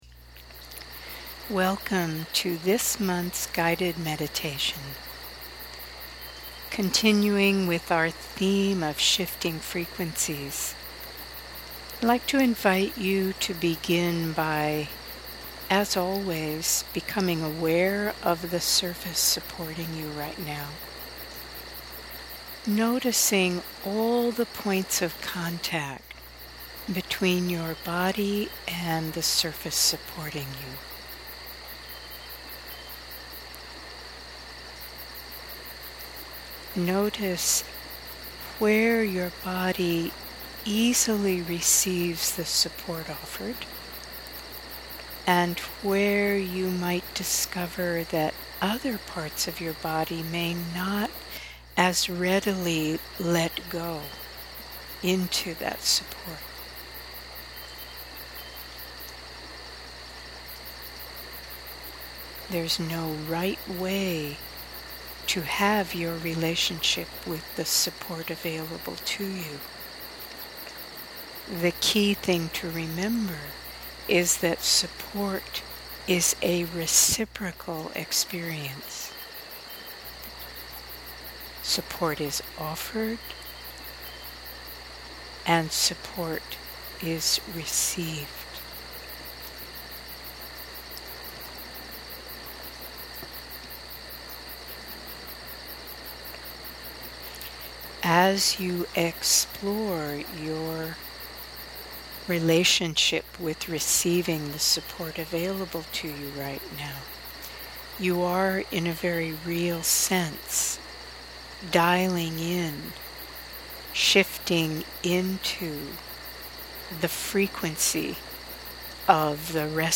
2021 May Audio Meditation
If you prefer to see images of nature as you follow the guided meditation, here’s our YouTube version: